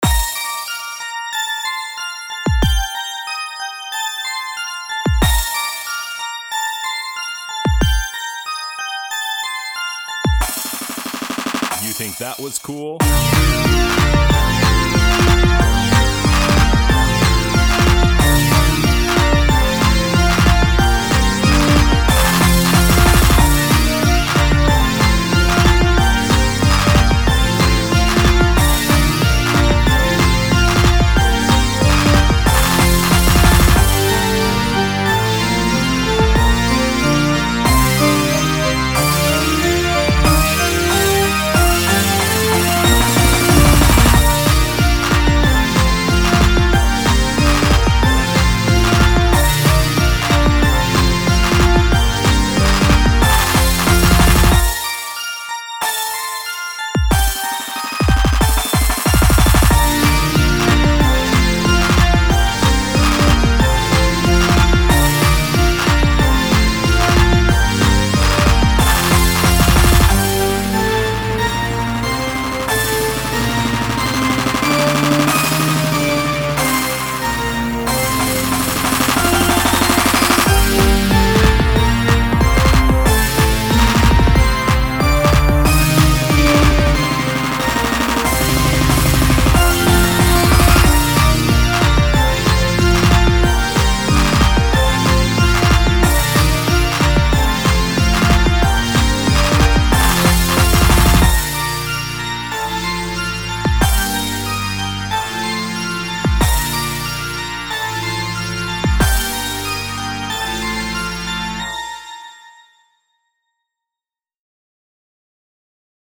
Male voice: Unknown (probably Fruity Loops sample)